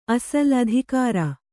♪ asal adhikāra